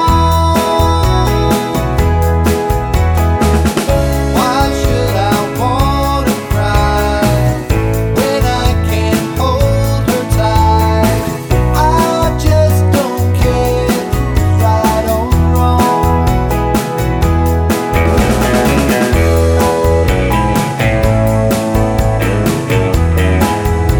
no Backing Vocals Easy Listening 2:30 Buy £1.50